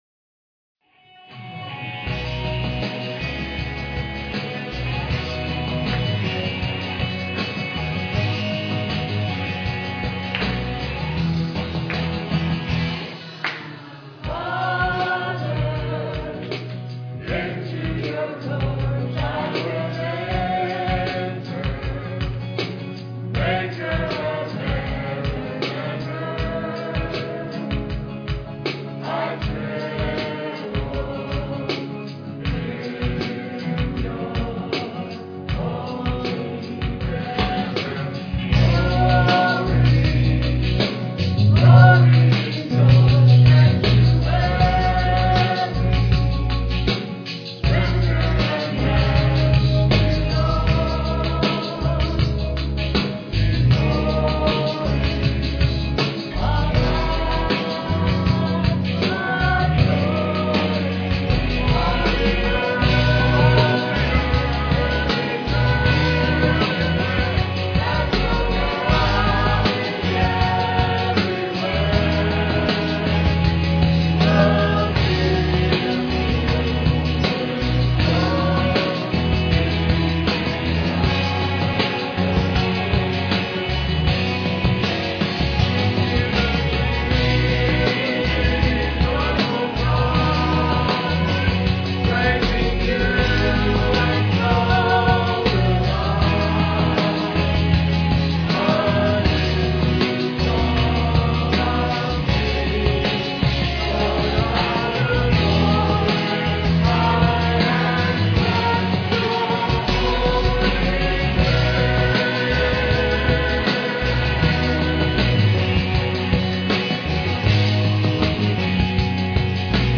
Piano and organ offertory